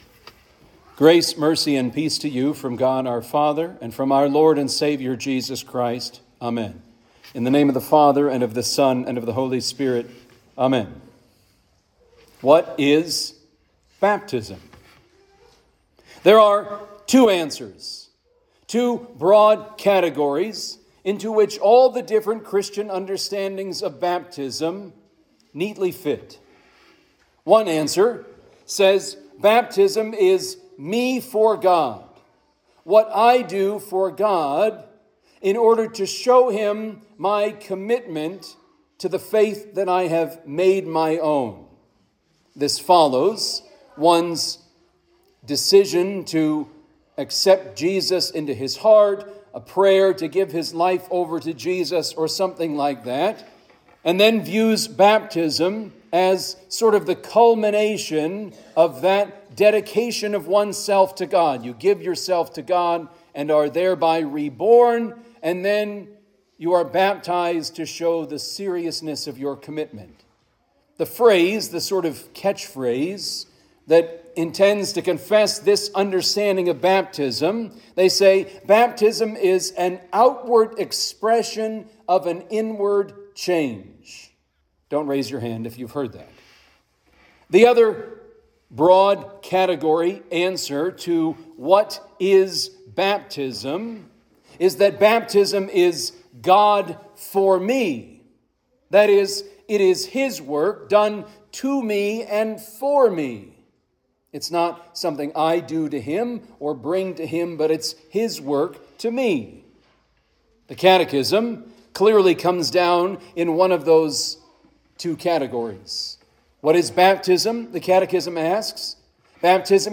Home › Sermons › Invocabit Wednesday, February 25